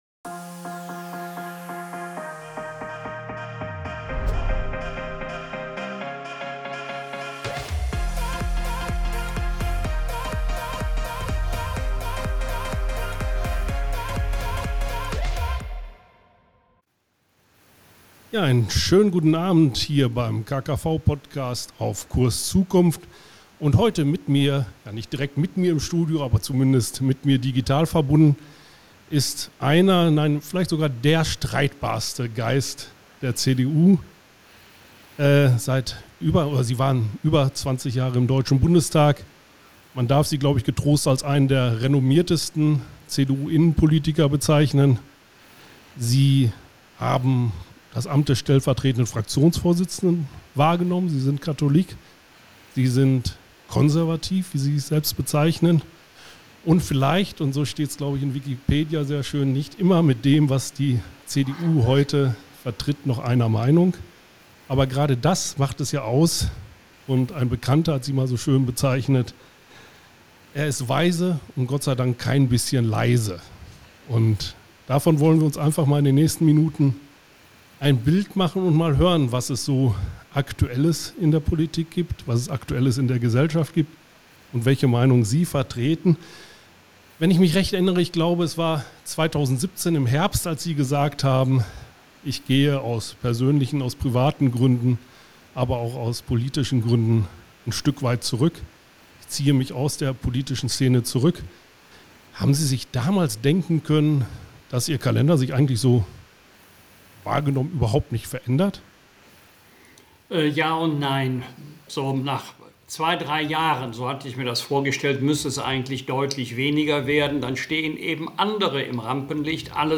KKV006 - Auf Kurs Zukunft - im Gespräch mit Wolfgang Bosbach ~ Auf Kurs Zukunft - Der KKV-Podcast Podcast